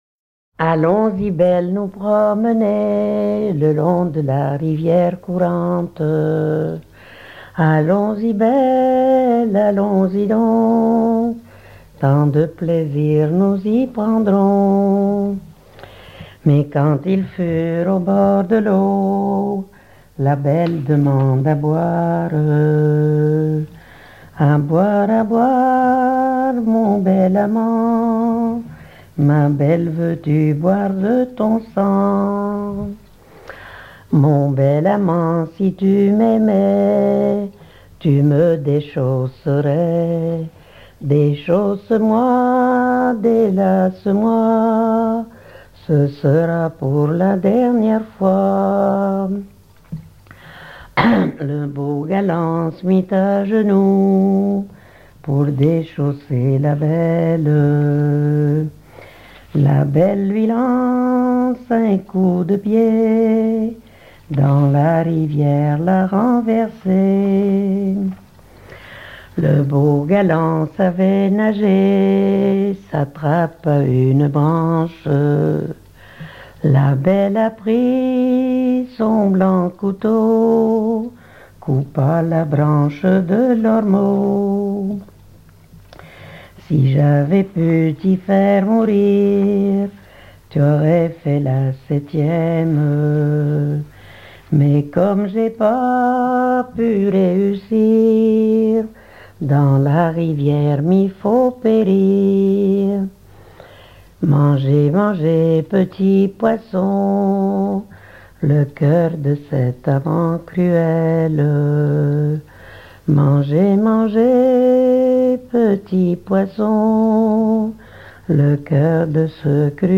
Localisation Commequiers
Genre strophique
Pièce musicale éditée